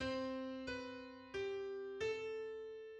Cambiata
The dissonance in the dissonant cambiata is approached by descending step and occurs on a weak half or quarter of the beat; the skip from the dissonance must be a descending third to a consonance; and this consonance must be followed by some number of ascending steps. This means there are only three possible essential dissonant cambiatas in third species: Two above the cantus firmus, with vertical intervals 8-7-5-6 (Ex.1) and 6-b5-3-4 (Ex.2); and one below the C.F., with 3-4-6-5 (Ex.3).